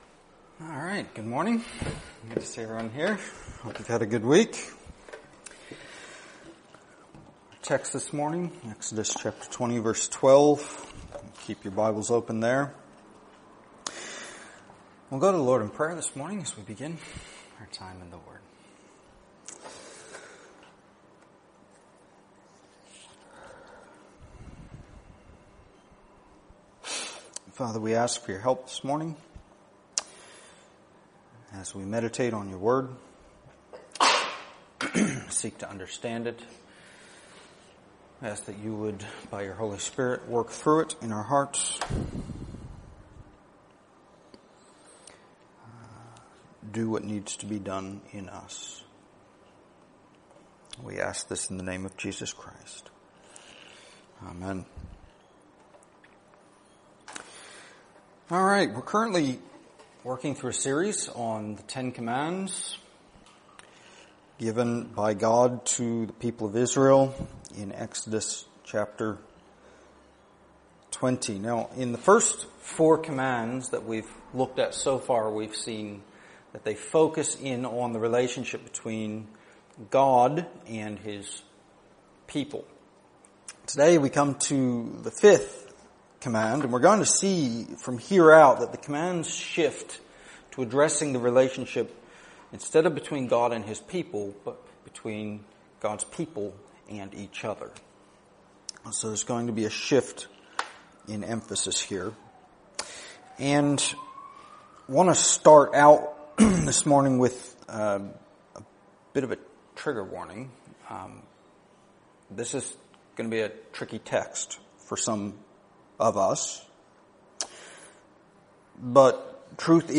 Download mp3 Previous Sermon of This Series Next Sermon of This Series